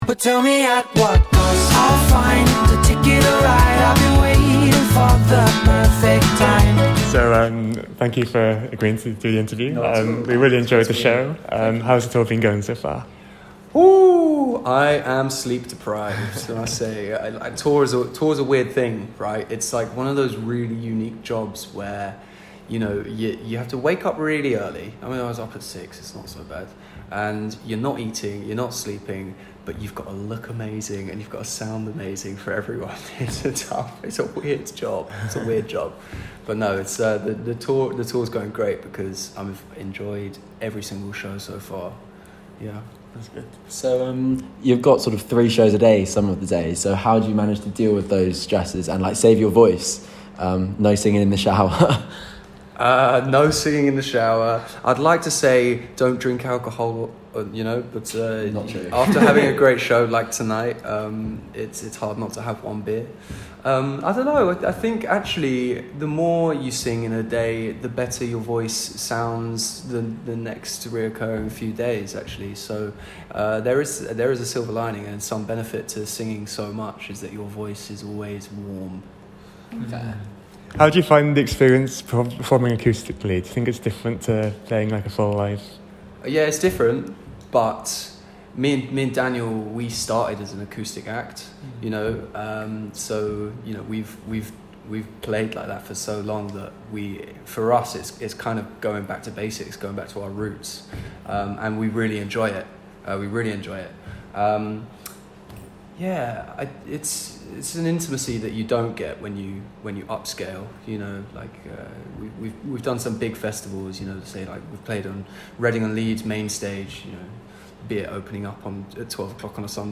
KAWALA Interview